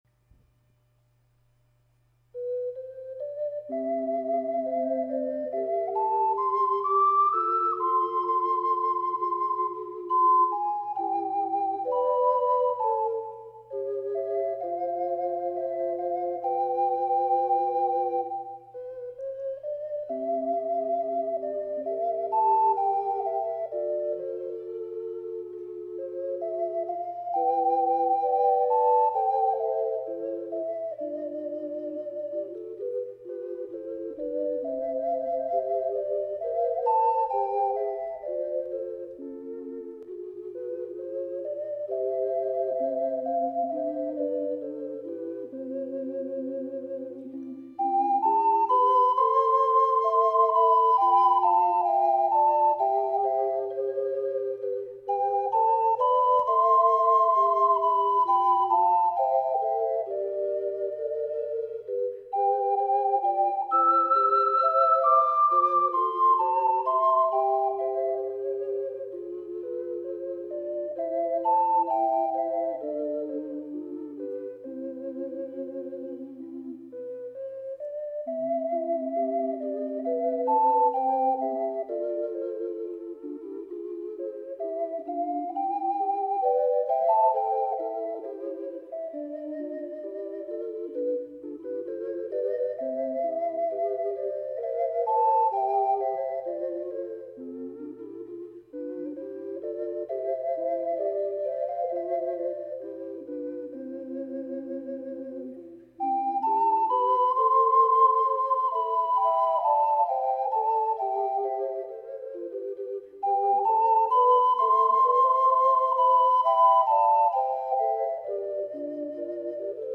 ③BC    みるとオカリナアカデミー　アンサンブル楽譜シリーズ(ピース楽譜）
試奏